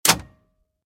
into_missile.ogg